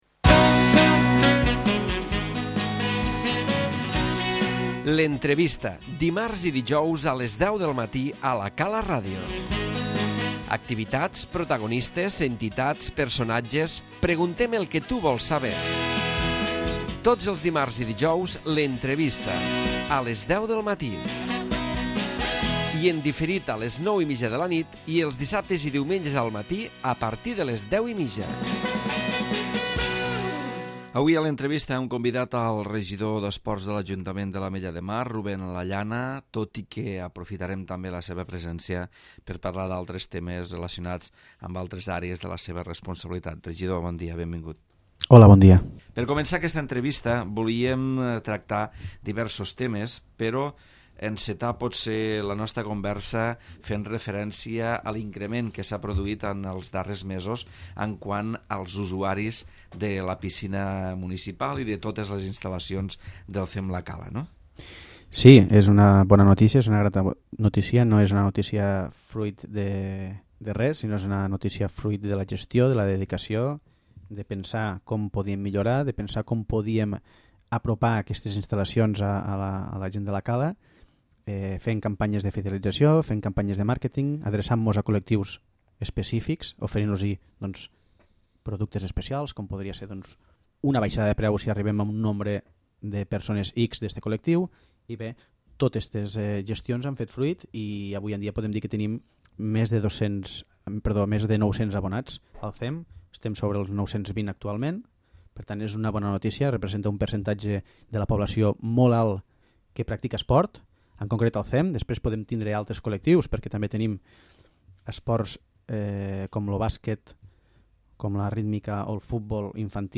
L'Entrevista
Ruben Lallana, regidor d'Esports i Gestió del territori ha parlat de diverses àrees de la seva responsabilitat, amb informacions sobre el CEM La Cala, el Parc Infantil dels diumenges a la tarda, el Mercat Nadalenc que organitza el Centre Social Tres